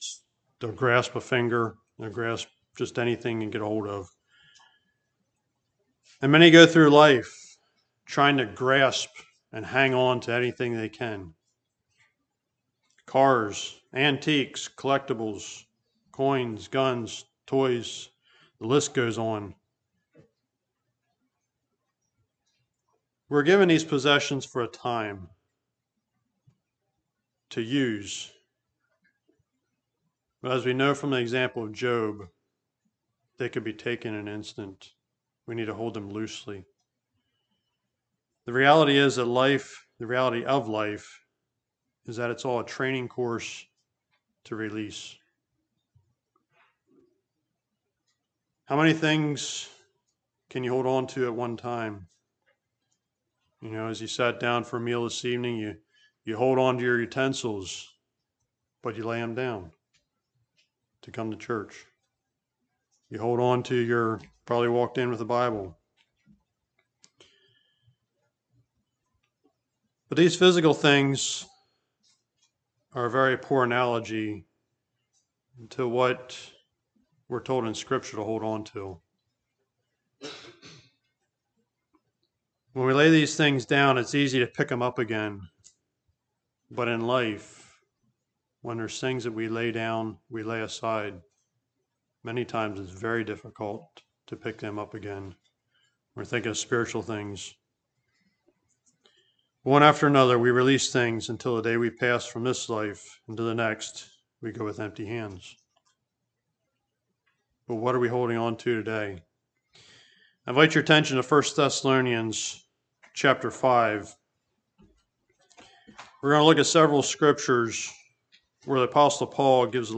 Listen to sermon recordings from Word of Life Mennonite Fellowship.